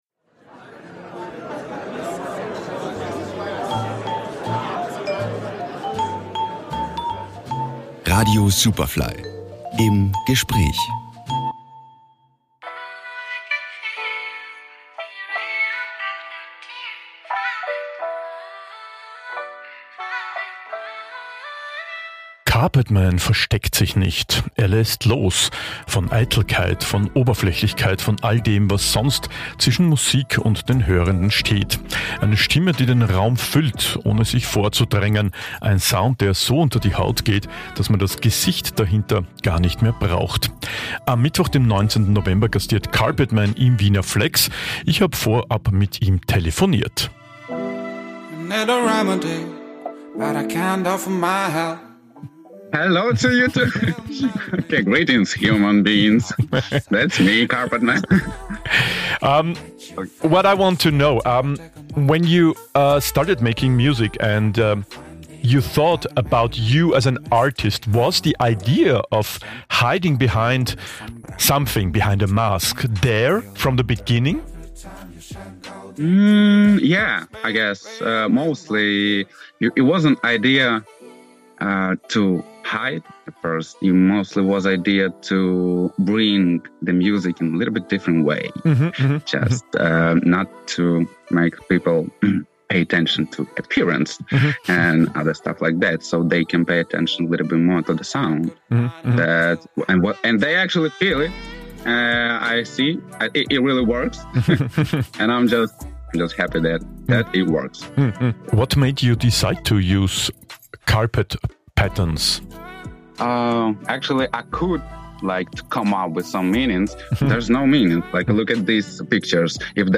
Superfly Im Gespräch